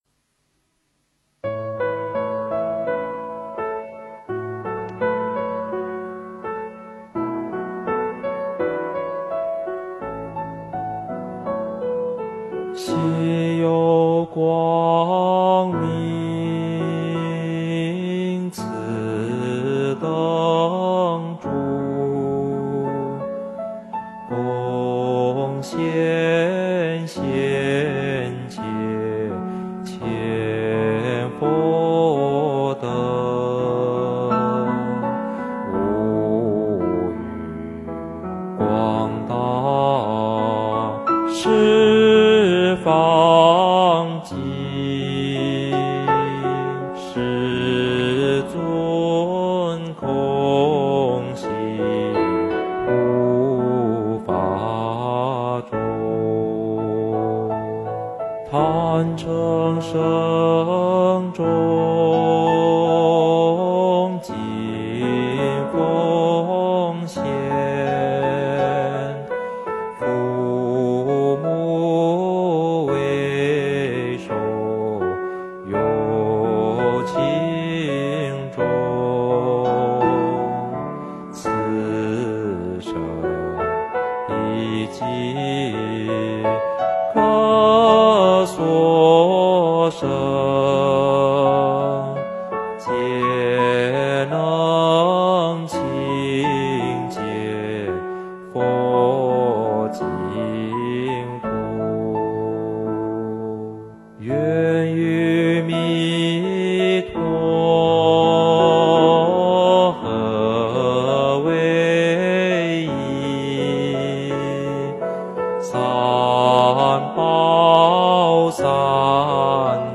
佛音 诵经 佛教音乐 返回列表 上一篇： 南无地藏菩萨 下一篇： 燃灯之歌 相关文章 佛说圣佛母般若波罗蜜多心经 佛说圣佛母般若波罗蜜多心经--王菲...